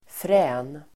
Uttal: [frä:n]